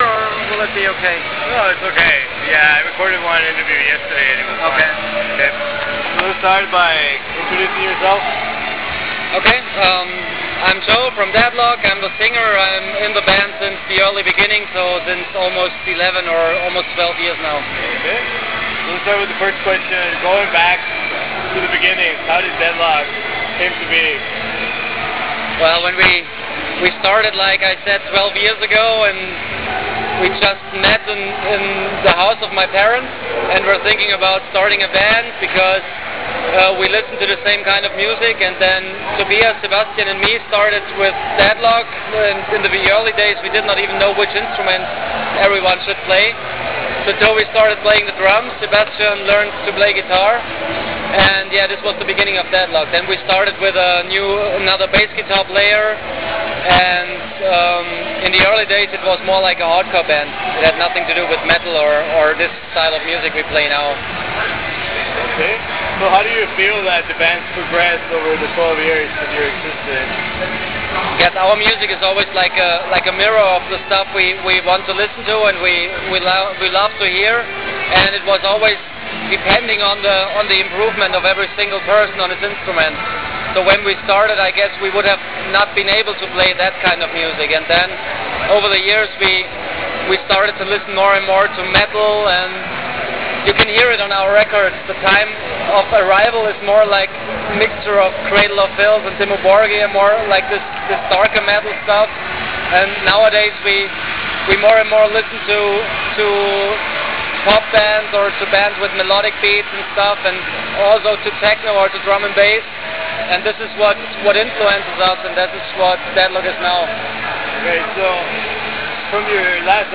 Interview With Deadlock